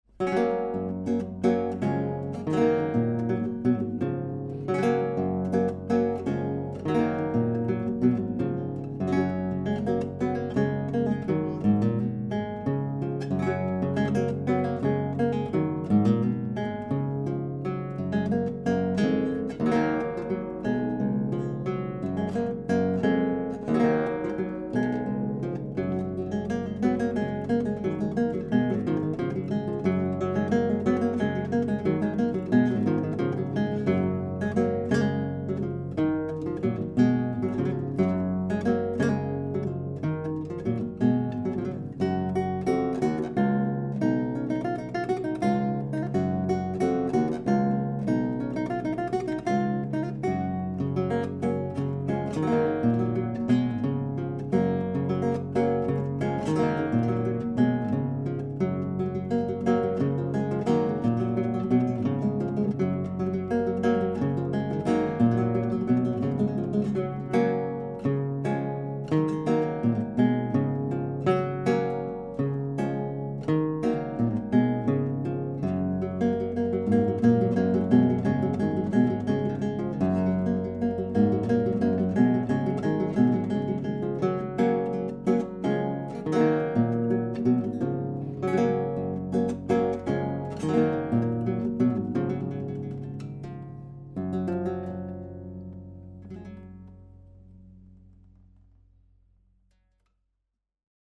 Auf Anfrage vom Tontechnikerforum 3db hier ein paar Samples, die die anerkannte Kombination CMC5 + MK2s von Schoeps mit dem vergleichsweise sehr günstigen Oktava MC012 mit Kugelkapsel vergleichen.
RME Fireface 800, Wohnzimmer, Abstand: ca. 0,5m, AB-Stereo mit Basis 30cm, keine Effekte oder EQ.
Chiaccona Oktava omni
ChiacconaMC012omni.mp3